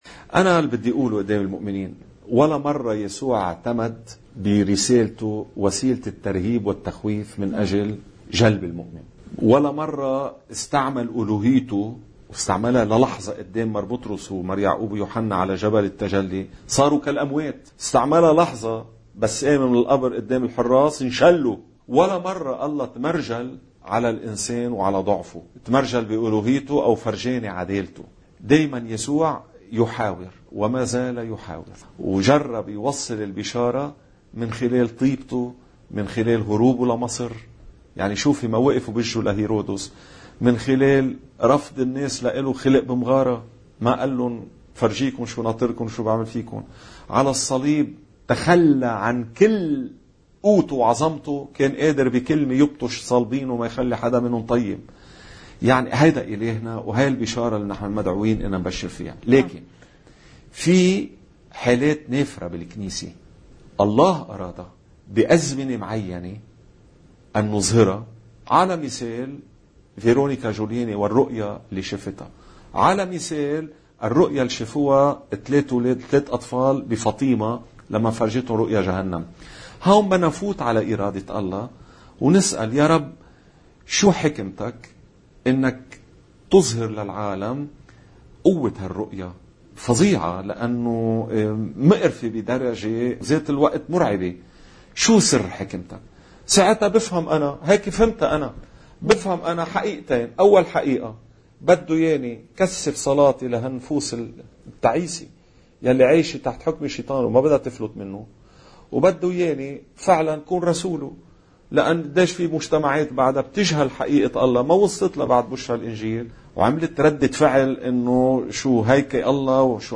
مقتطف من حديث